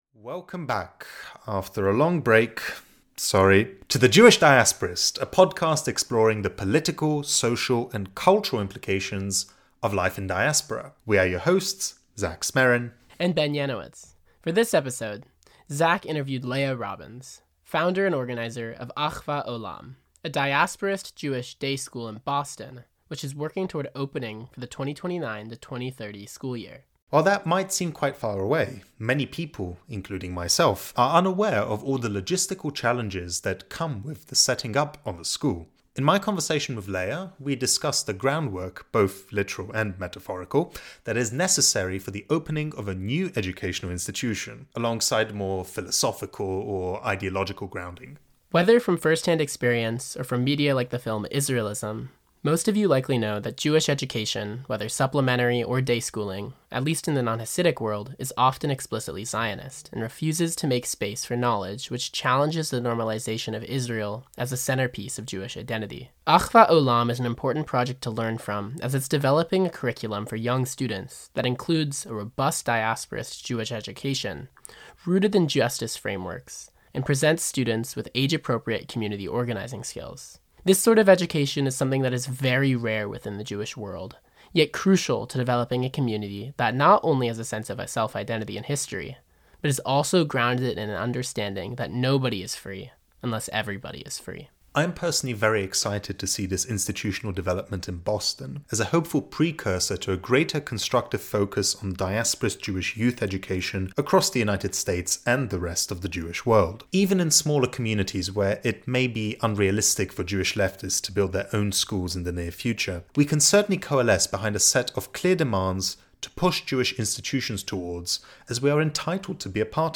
(apologies for the audio being a bit rusty